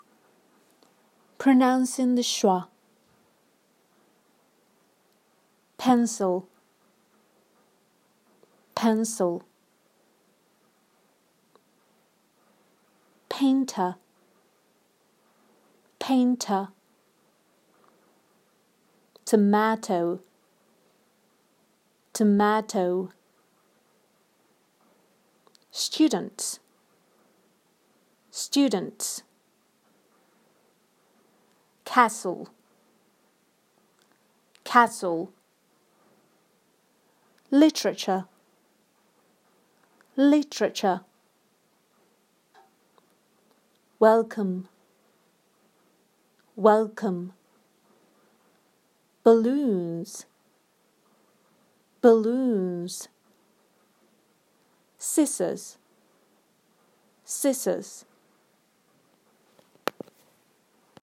Listen and underline the /Ə/ sound and syllable in the words below.